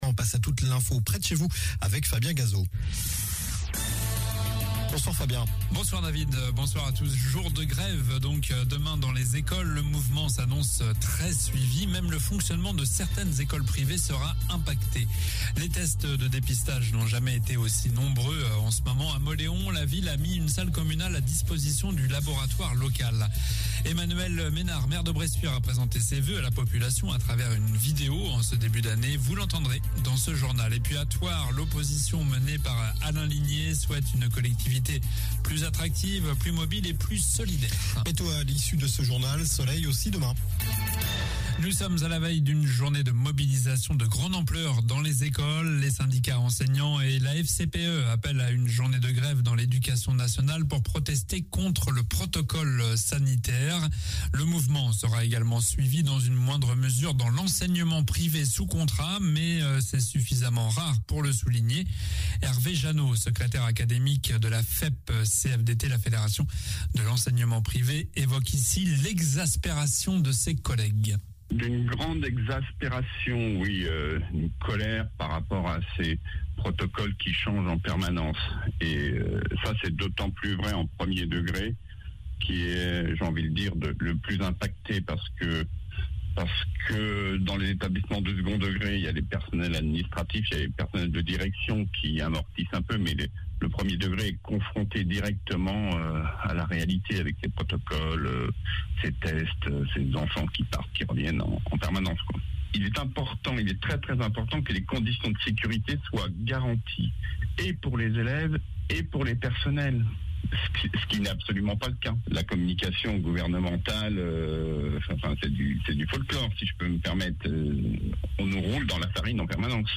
Journal du mercredi 12 janvier (soir)